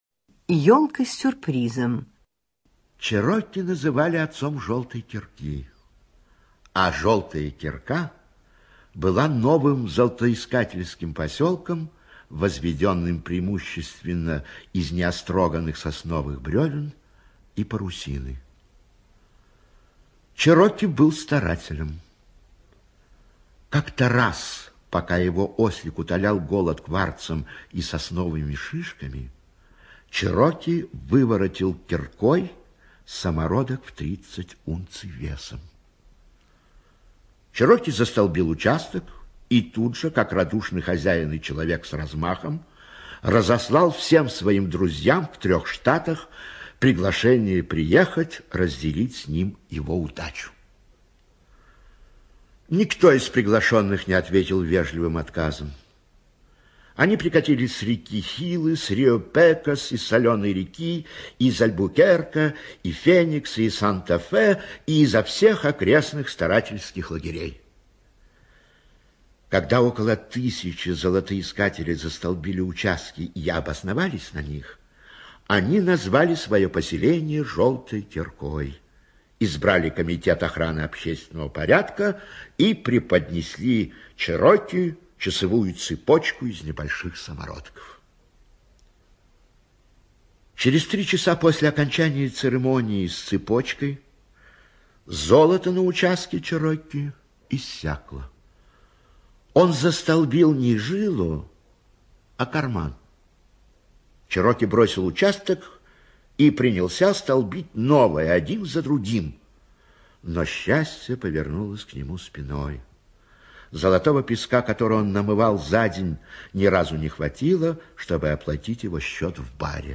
ЧитаетЯкут В.